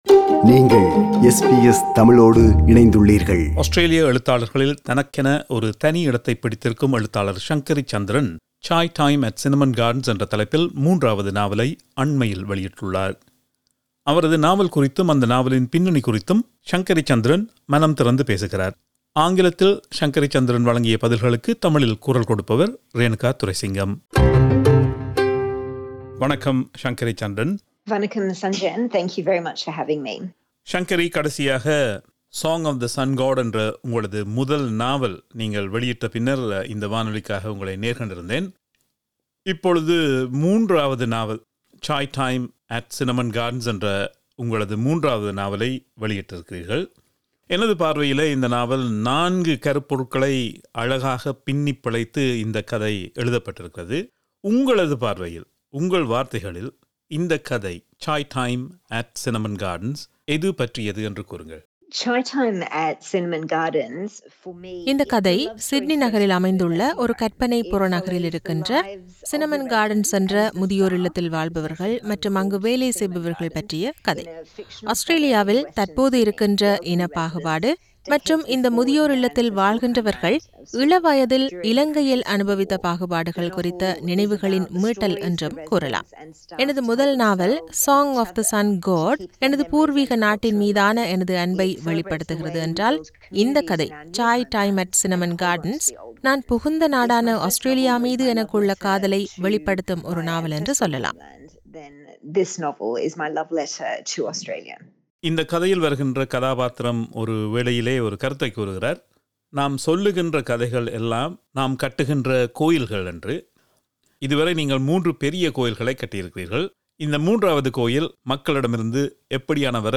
பதில்களுக்குத் தமிழில் குரல் கொடுத்திருப்பவர்